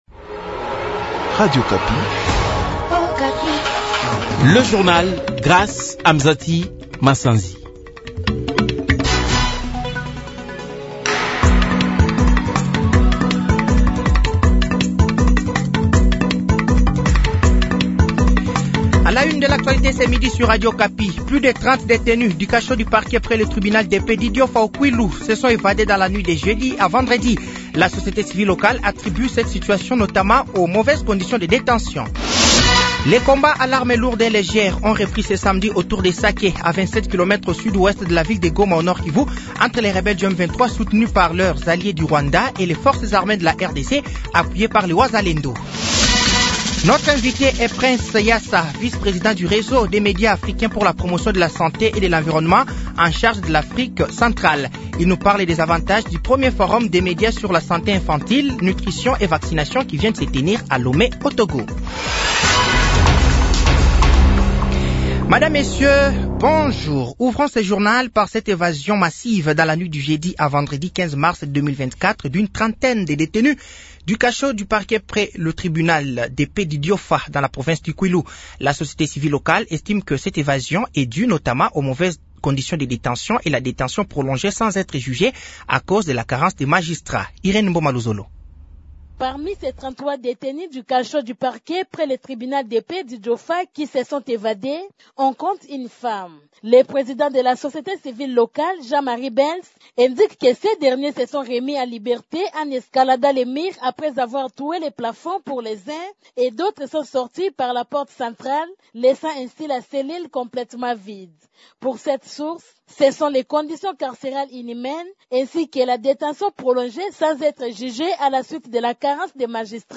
Journal français de 12h de ce samedi 16 mars 2024